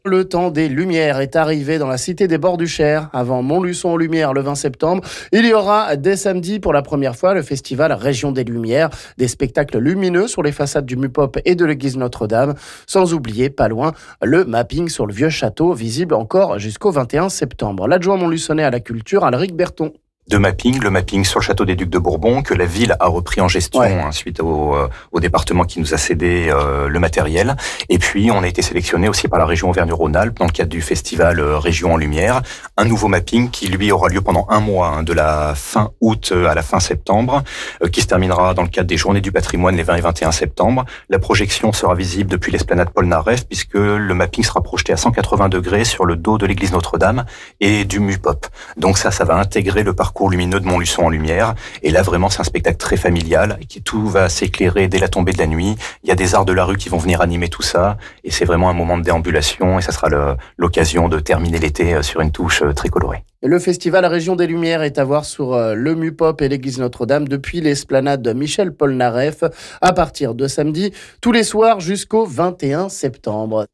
On en parle ici avec l'adjoint montluçonnais à la culture Alric Berton...